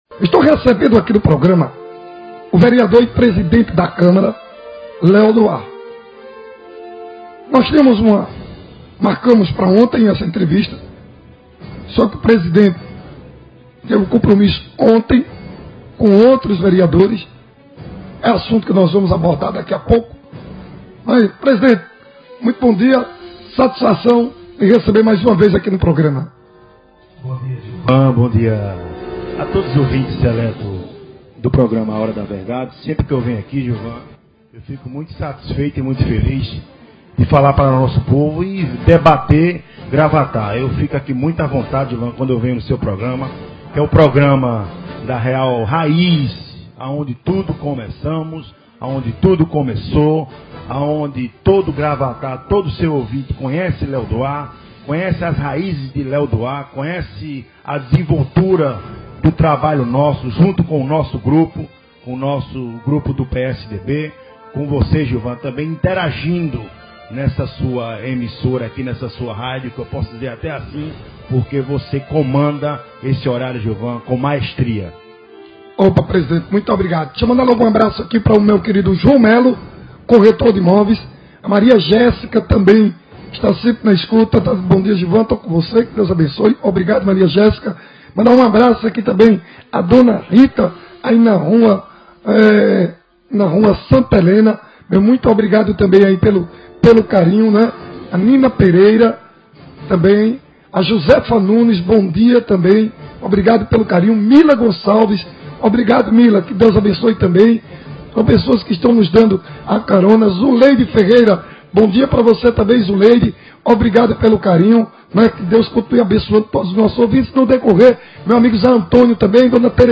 ÁUDIO: Em entrevista no rádio, presidente da Câmara de Gravatá sai em defesa do governo municipal | Pernambuco Notícias
ENTREVISTA-LÉO-DO-AR-32k.mp3